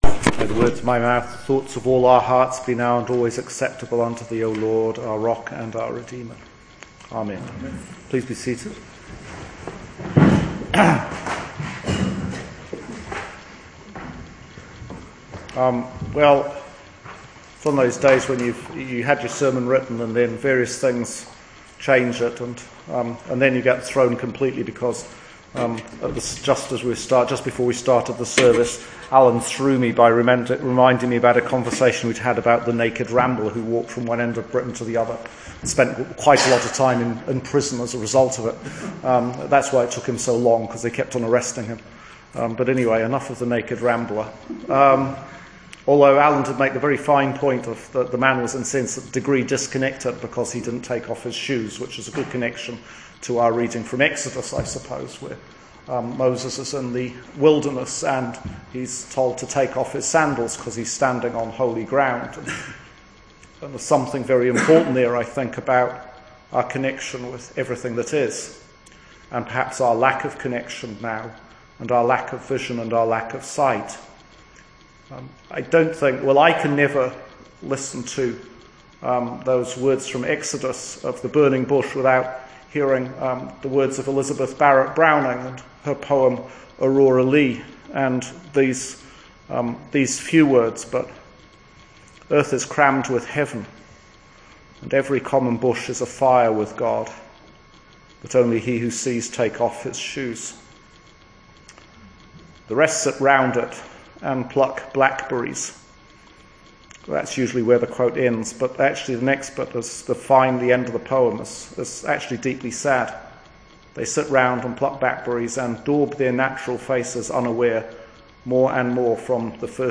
Sermon notes for Eleventh Sunday after Trinity Year B 2018